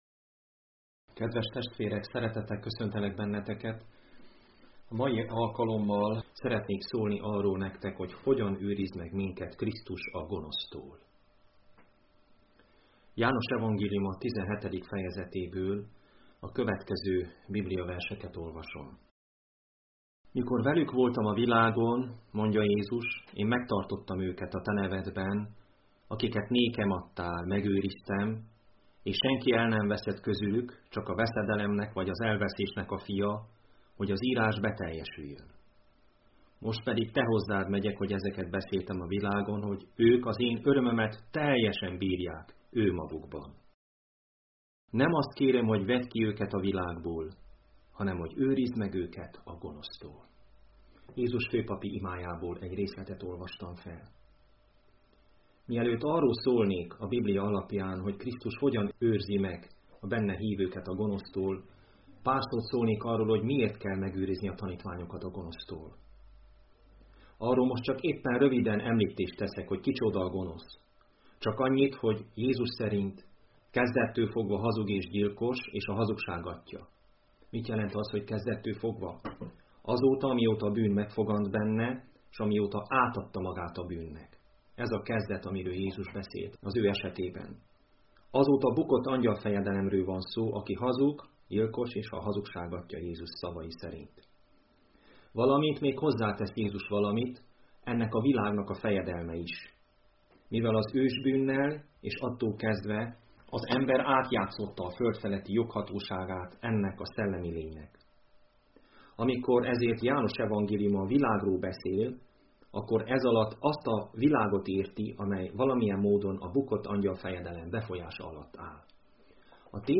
Igehirdetések mp3 Link az igehirdetéshez Hasonló bejegyzések Igehirdetések mp3 Bűnrendezésről.